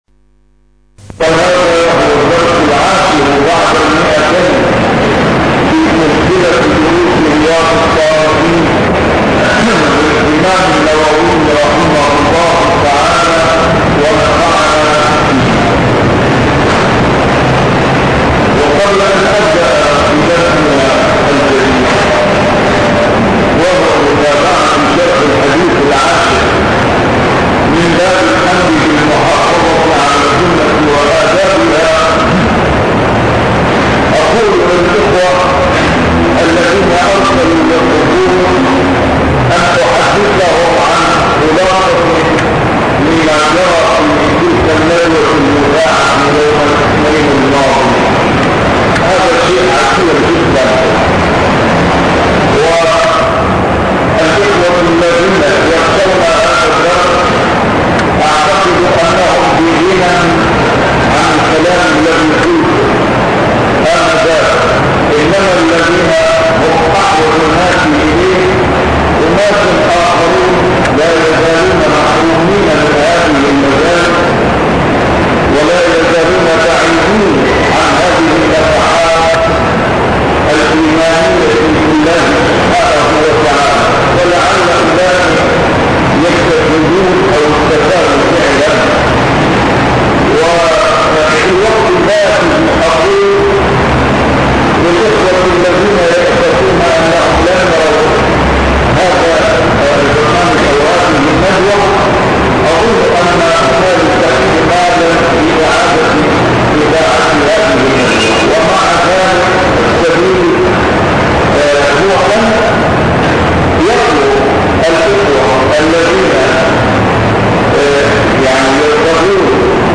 A MARTYR SCHOLAR: IMAM MUHAMMAD SAEED RAMADAN AL-BOUTI - الدروس العلمية - شرح كتاب رياض الصالحين - 210- شرح رياض الصالحين: المحافظة على السنة